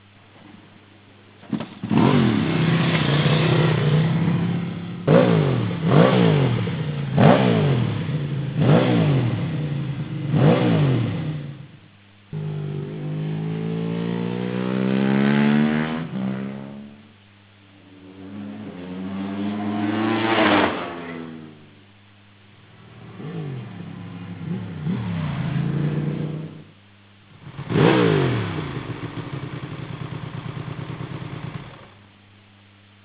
Ducati Sound
...und wenn's Motoerli schoen warm isch, toents eso :-O !!!